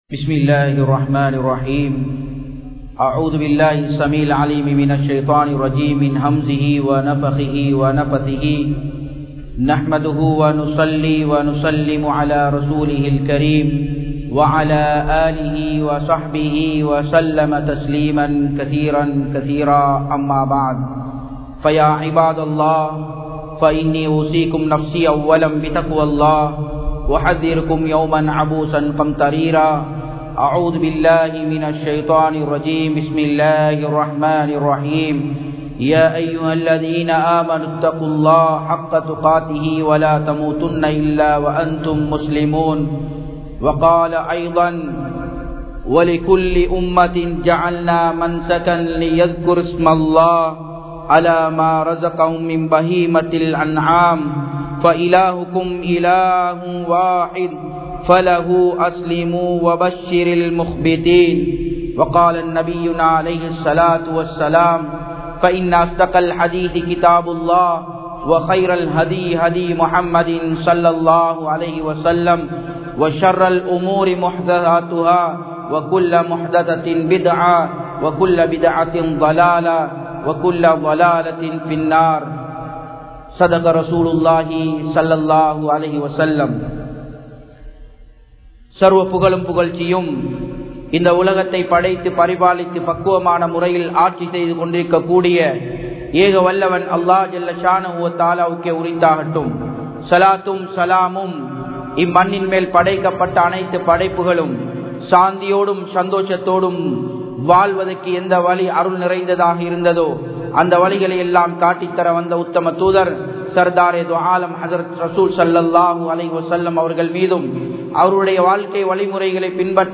Ulhiya (உழ்ஹிய்யா) | Audio Bayans | All Ceylon Muslim Youth Community | Addalaichenai
Japan, Nagoya Port Jumua Masjidh 2017-08-18 Tamil Download